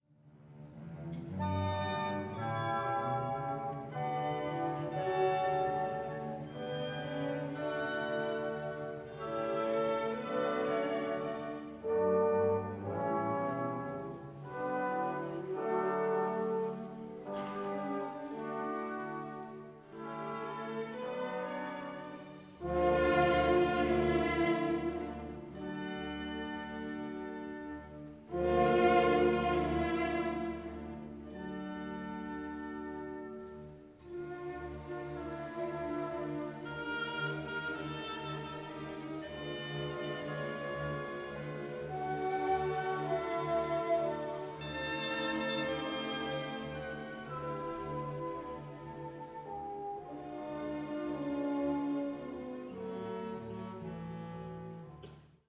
in B minor
Recorded live in Leningrad, Russia, 17 October 1982